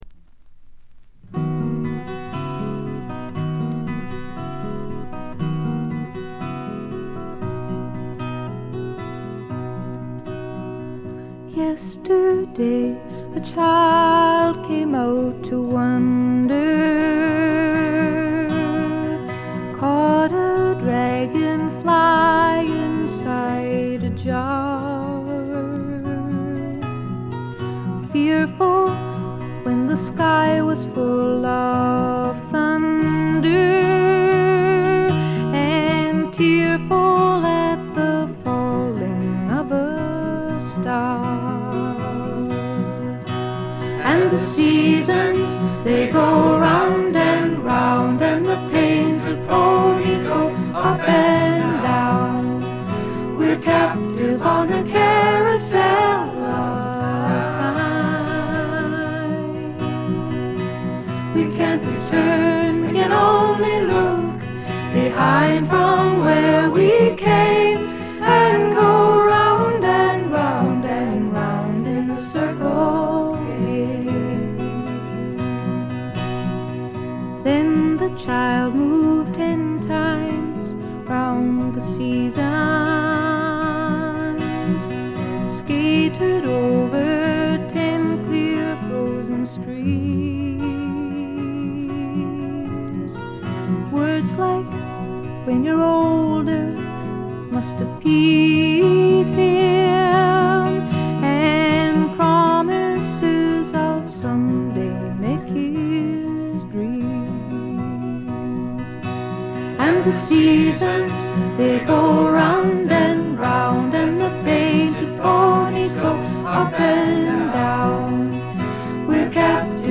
軽微なバックグラウンドノイズ、チリプチ。散発的なプツ音少し。
試聴曲は現品からの取り込み音源です。